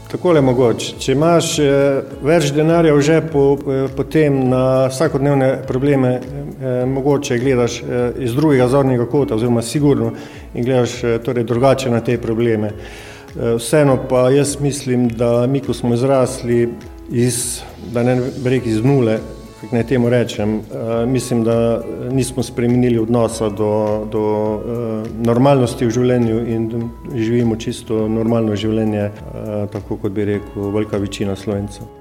Takole je v zadnjem pogovoru za Koroški radio odgovoril na vprašanje, kaj mu pomeni bogastvo: